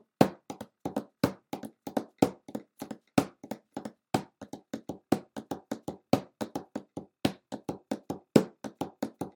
what the actual heck are those drums doing?? it sounds incredible, but how the actual heck was that made???